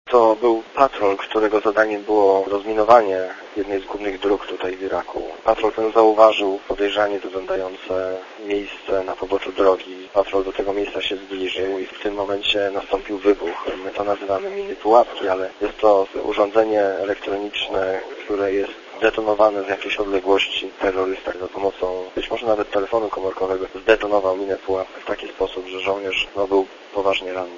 Dla radia ZET mówi